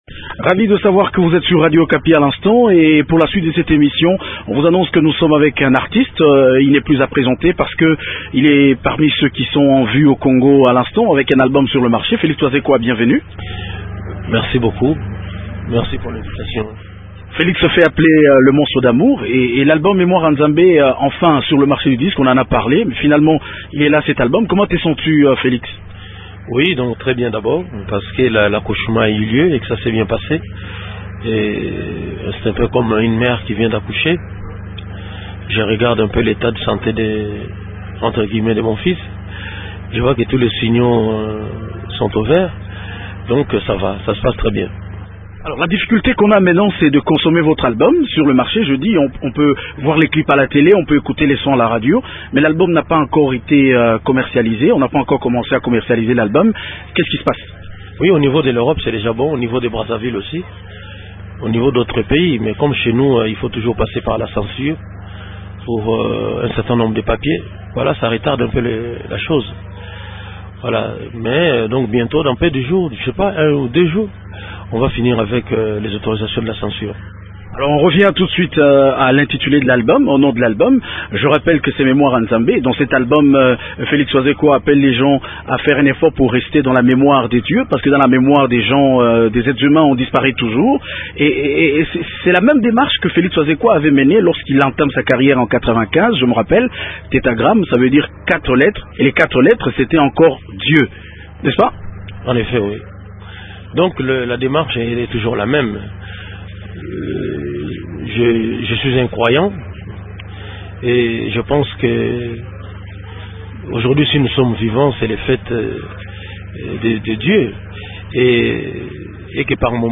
L’artiste s’exprime sur son œuvre. Il réagit aussi aux rumeurs qui le traitent pour un homme recherché par la justice française pour trafic d’êtres humains.